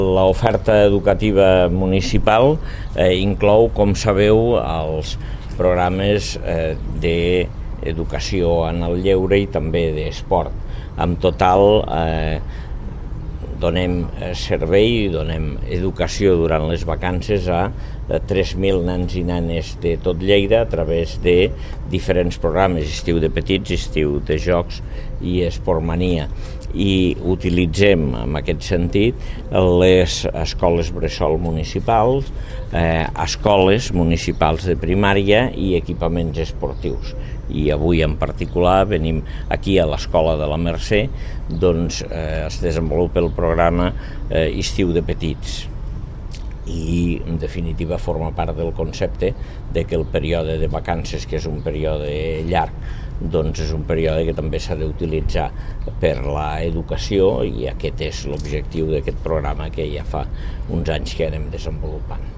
tall-de-veu-dangel-ros-sobre-els-casals-destiu-municipals-de-lleida